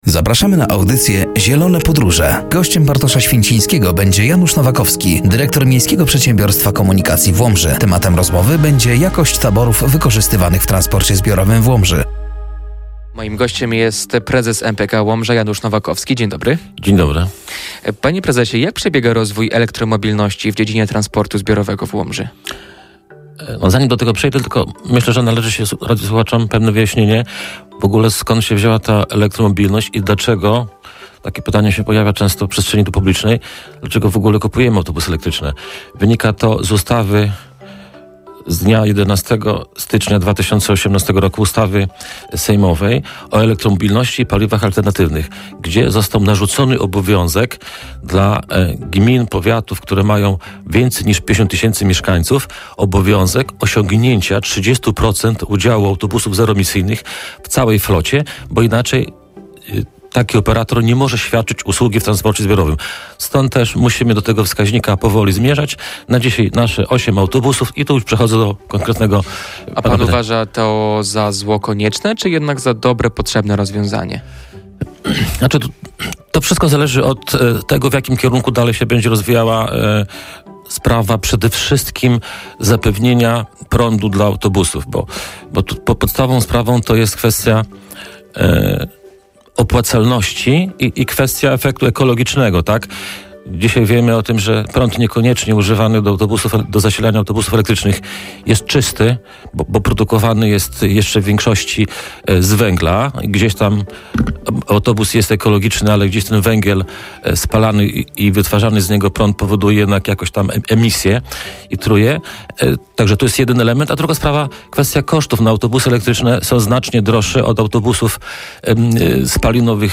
“Zielone Podróże” w środę o g. 10.30 na antenie Radia Nadzieja.